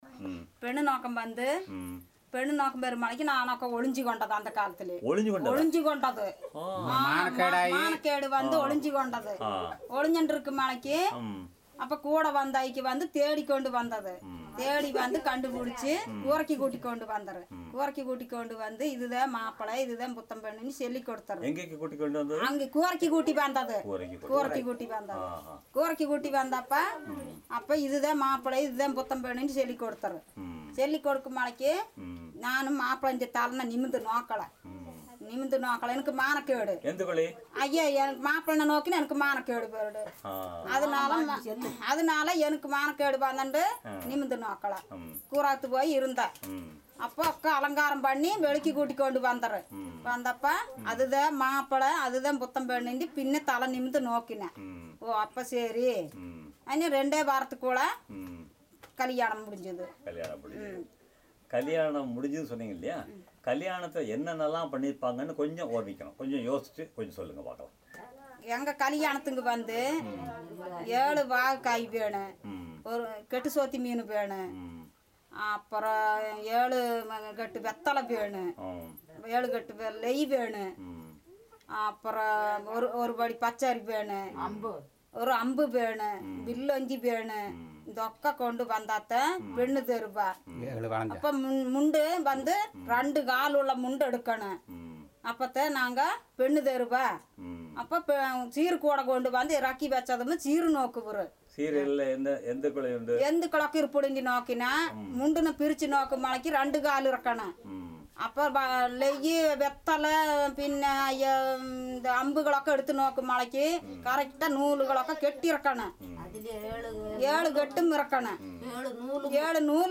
Conversation about wedding ceremonies